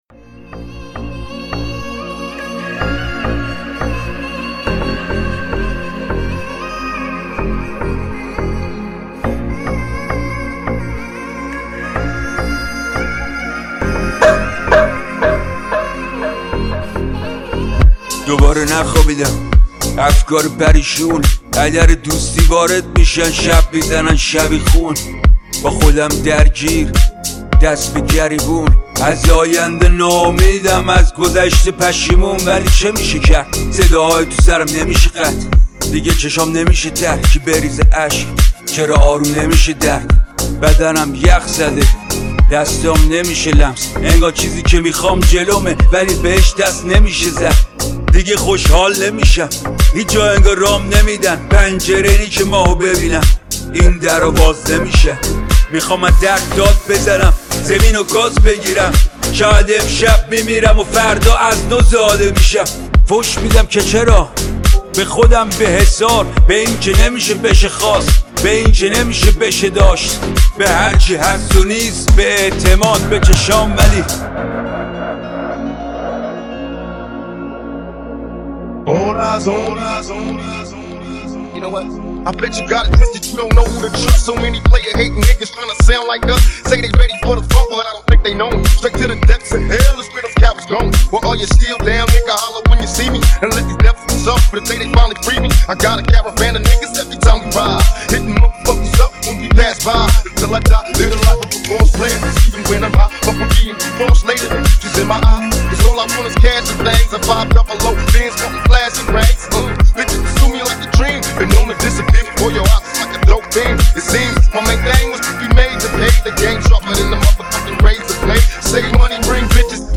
DEEP HOUSE VOCAL